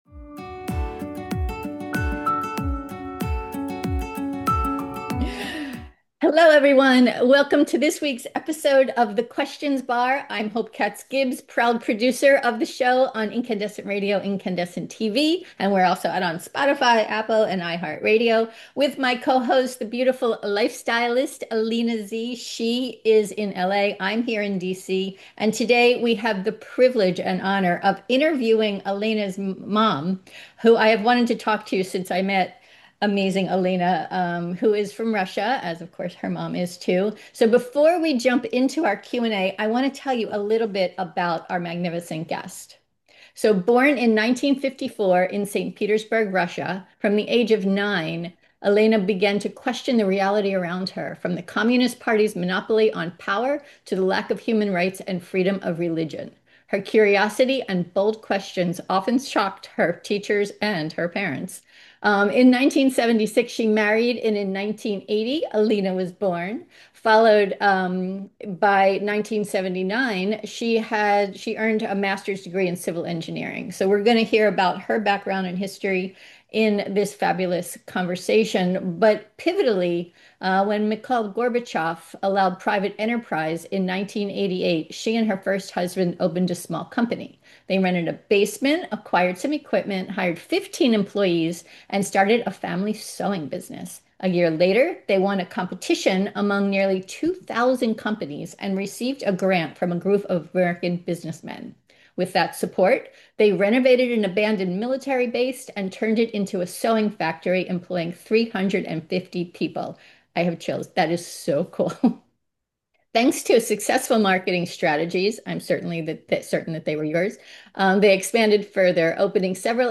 Check out Ep16 of the show where we interview a woman who teaches that inner-knowing, and not taking shit, guides powerful women to live the life of their dreams!